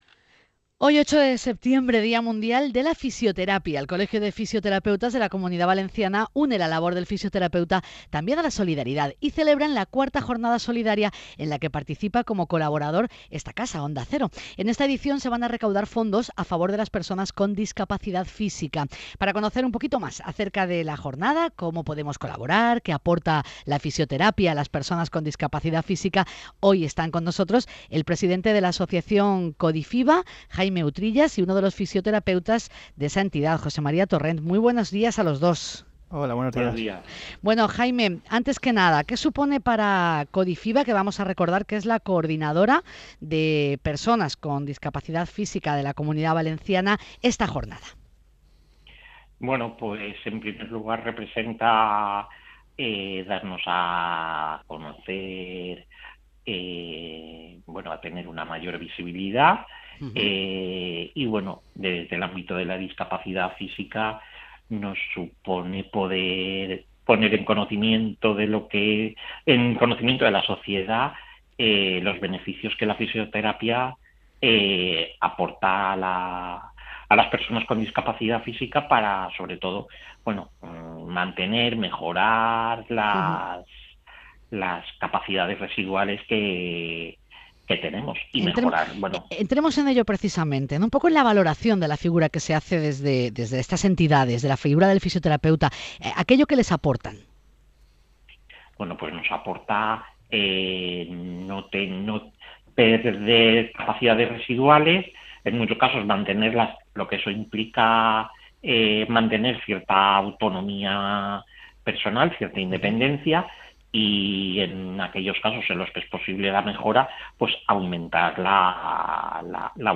La entrevista ha sido emitida en los programas “Alicante en la Onda”, “Castellón en la Onda”, y “Valencia en la Onda”.